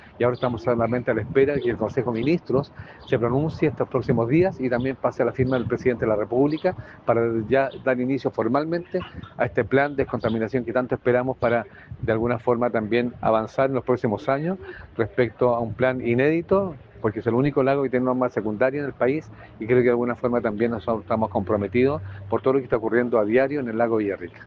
Al respecto, el alcalde de Villarrica, Pablo Astete, sostuvo que están esperando el inicio formal del plan.
cuna-alcalde-villarrica.mp3